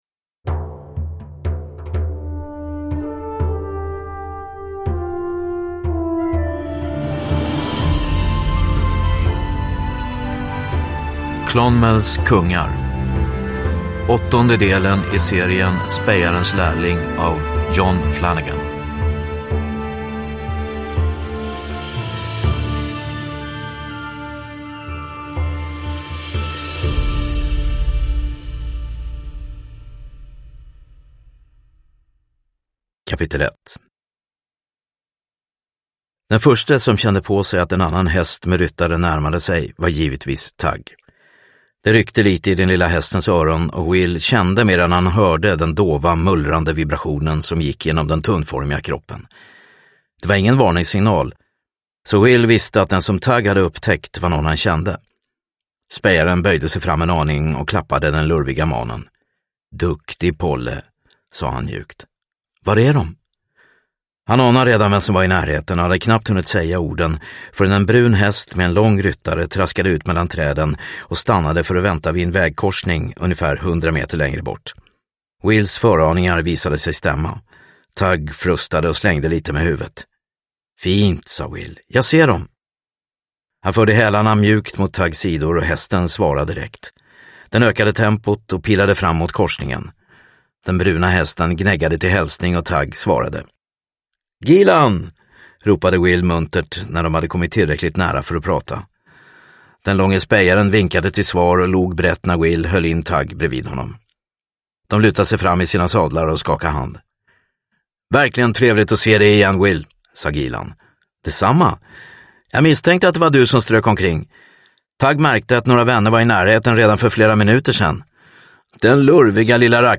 Clonmels Kungar – Ljudbok – Laddas ner